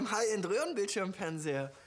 Roehrenbildschirmfernseher.wav